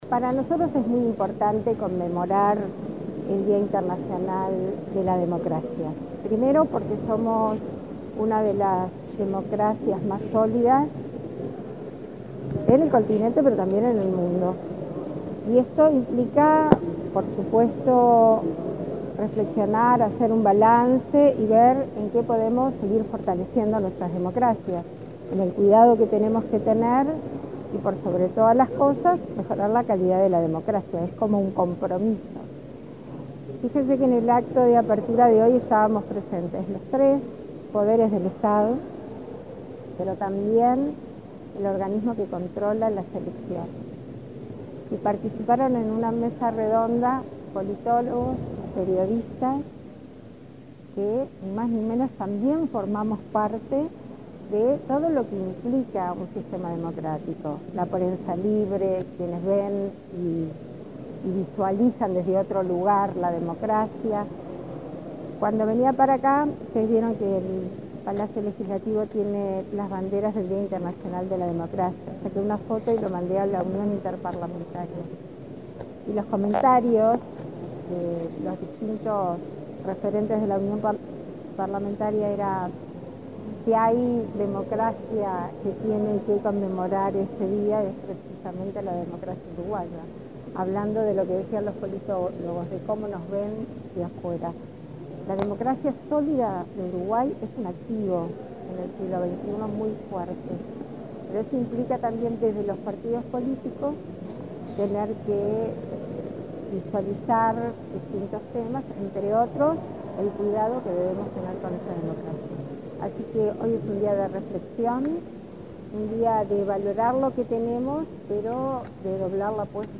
Declaraciones de la presidenta en ejercicio, Beatriz Argimón, a la prensa
Declaraciones de la presidenta en ejercicio, Beatriz Argimón, a la prensa 15/09/2023 Compartir Facebook X Copiar enlace WhatsApp LinkedIn La presidenta en ejercicio, Beatriz Argimón, dialogó con la prensa en el Palacio Legislativo, antes de participar en el acto por el Día Internacional de la Democracia.